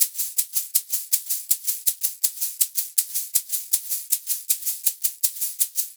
80 SHAK 07.wav